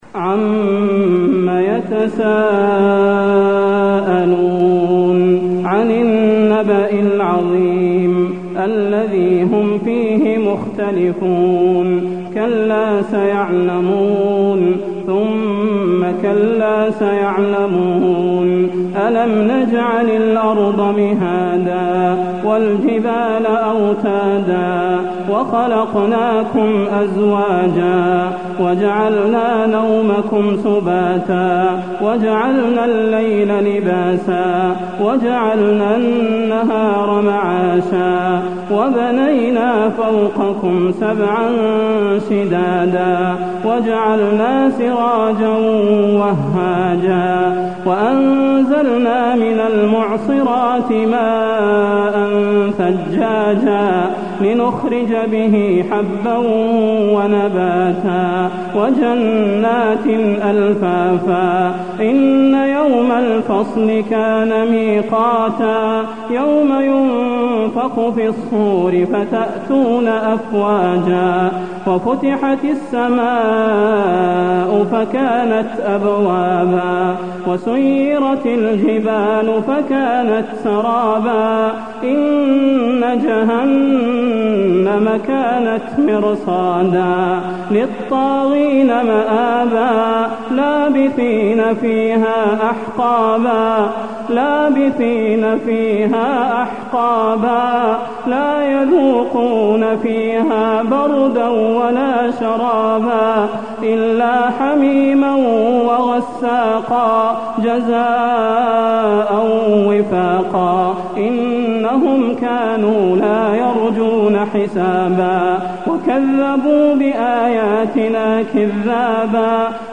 المكان: المسجد النبوي النبأ